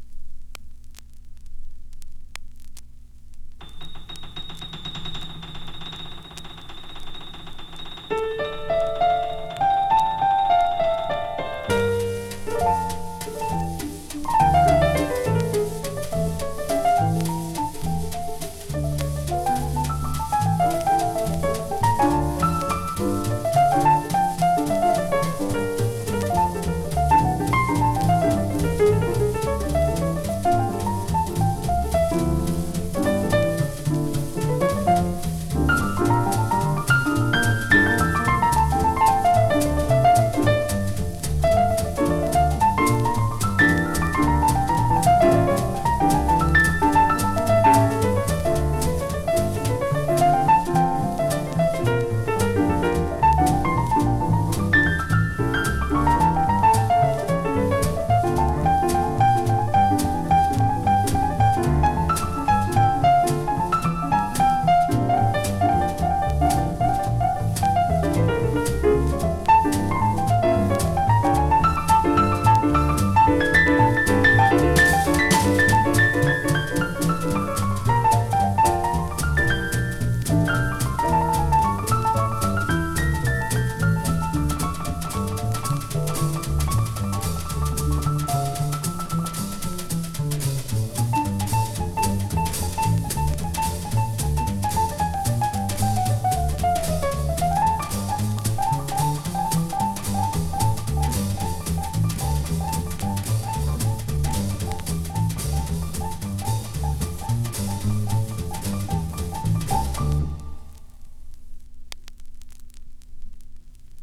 Recorded:  1960 in Paris, France
Bass
Drums